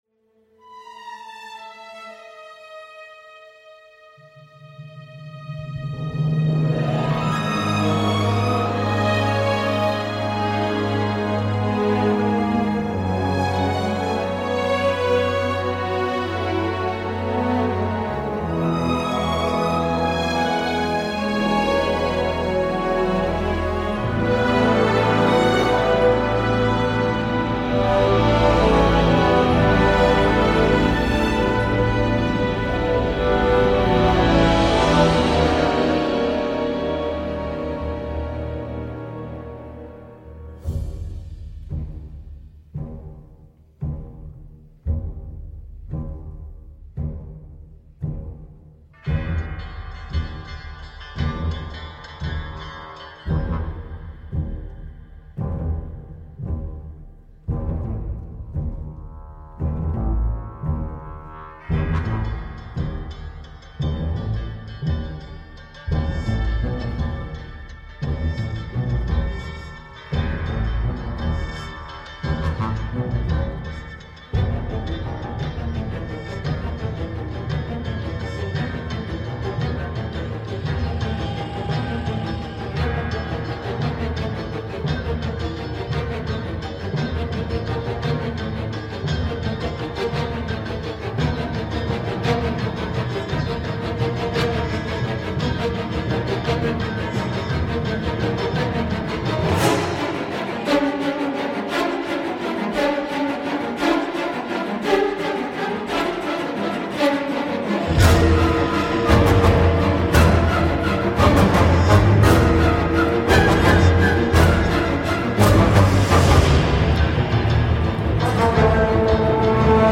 ample, élégante et mystérieuse
entre danger et romantisme.